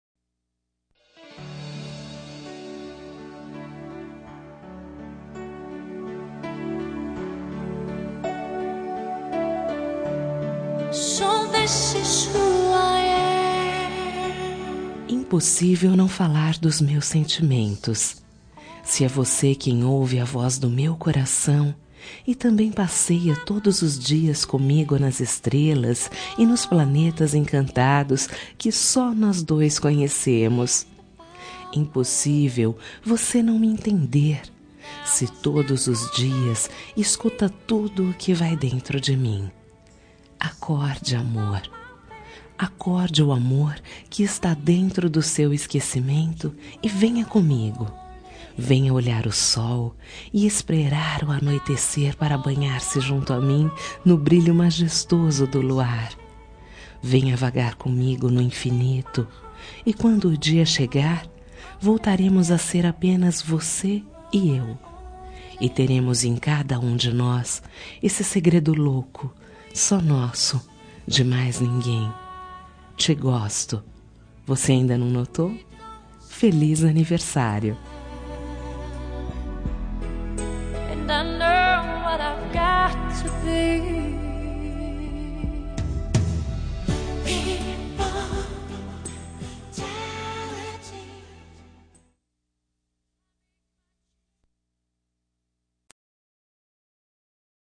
Telemensagem Aniversário de Paquera -Voz Feminina – Cód: 1239